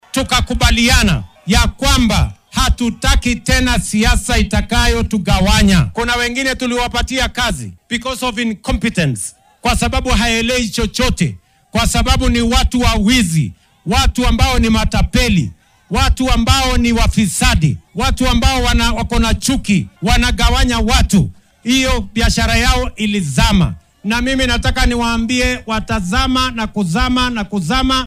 Hasa ahaate , hoggaamiyaha qaranka ayaa xilli uu ku sugnaa deegaanka Luanda ee ismaamulka Vihiga sheegay in Gachagua uunan lahayn mustaqbal siyaasadeed.